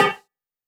059 HiBongo LoFi.wav